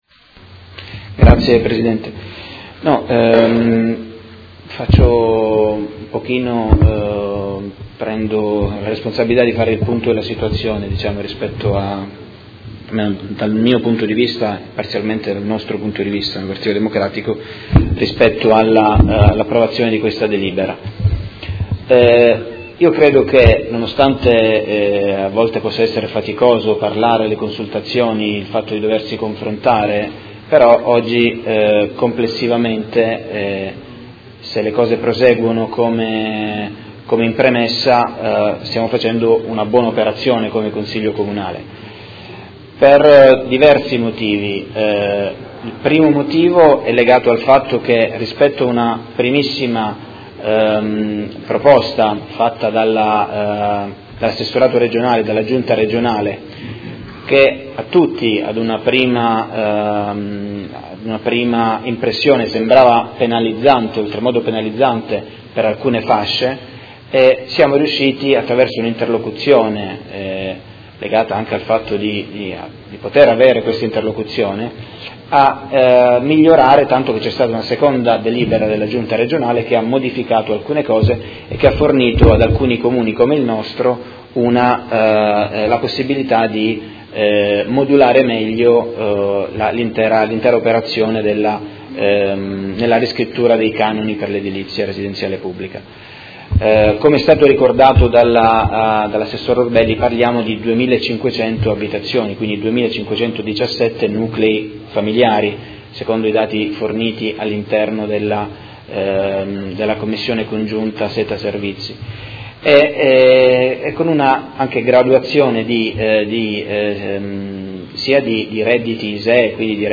Tommaso Fasano — Sito Audio Consiglio Comunale
Seduta del 5/04/2018. Dibattito su proposta di deliberazione: Approvazione Regolamento per la definizione delle modalità di calcolo e di applicazione dei canoni di locazione degli alloggi di edilizia residenziale pubblica con decorrenza 1 ottobre 2017, Ordini del Giorno ed emendamento